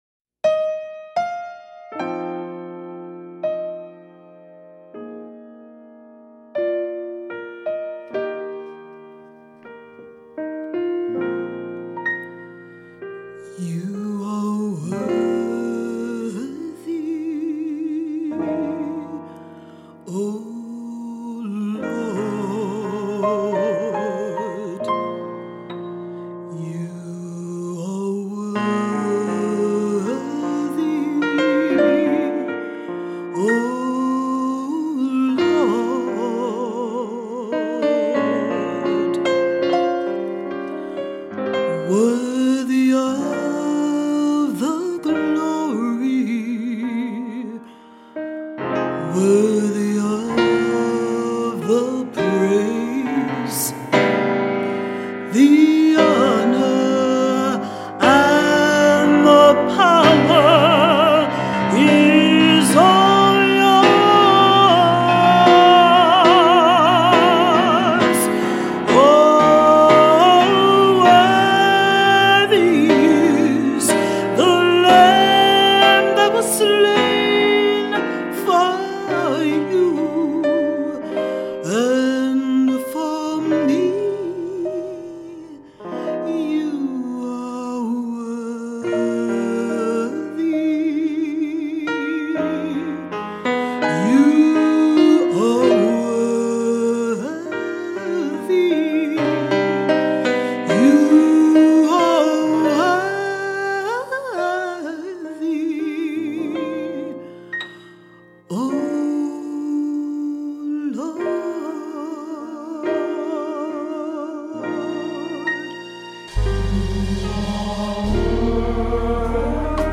Voicing: SATB; Solo; Assembly